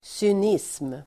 Ladda ner uttalet
Uttal: [syn'is:m]